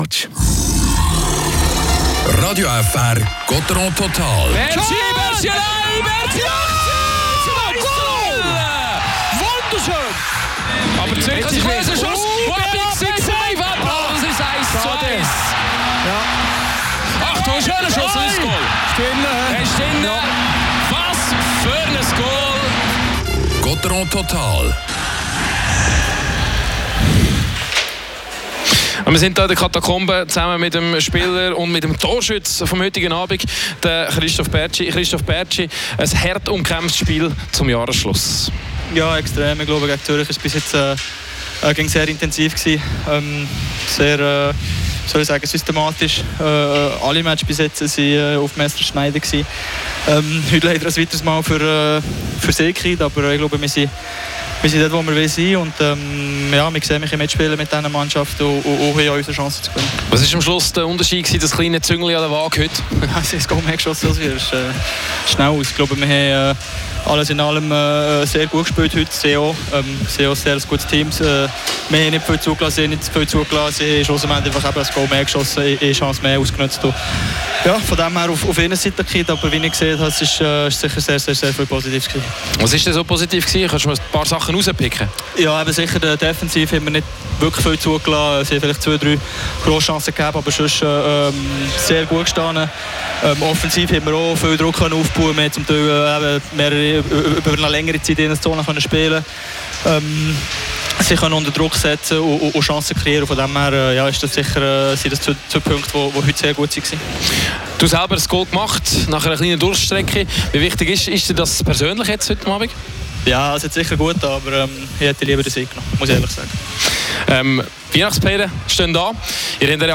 Interview mit Christoph Bertschy.